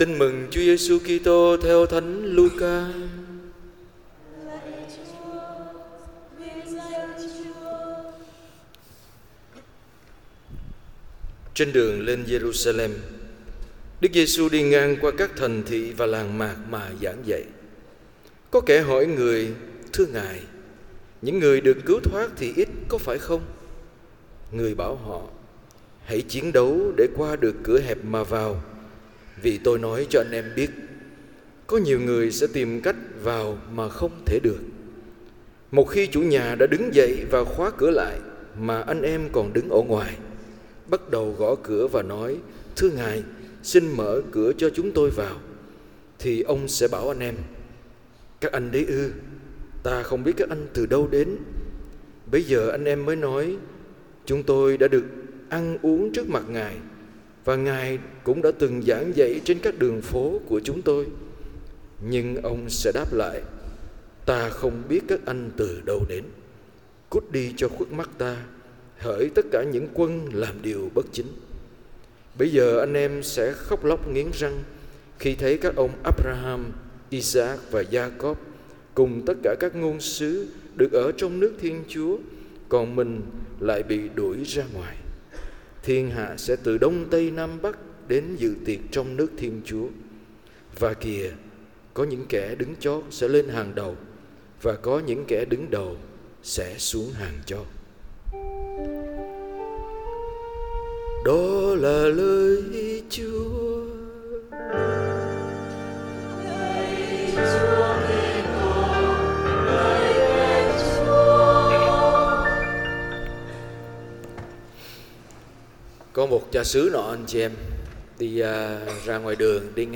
Bai-giang_CN_XXI_TN_C.mp3